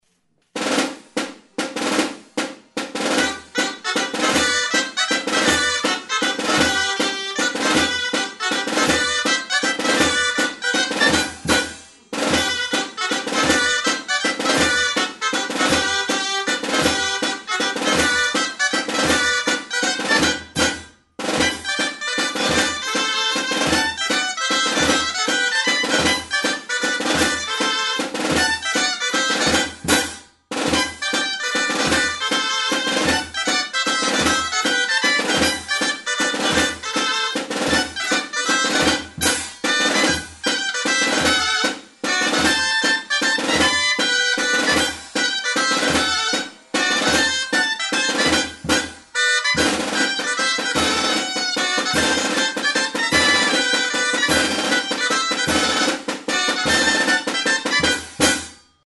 Aerófonos -> Lengüetas -> Doble (oboe)
MAZURKA. Iruñeko Ezpelur taldea.
EUROPA -> EUSKAL HERRIA
Mihi bikoitzeko soinu-tresna da.